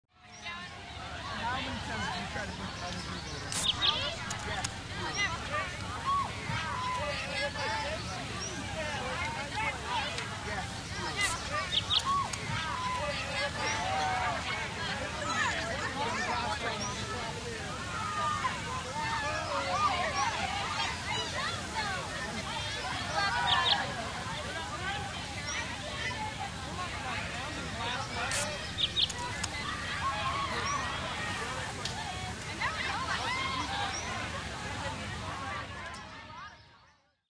Звук радости и забав на природе массового праздника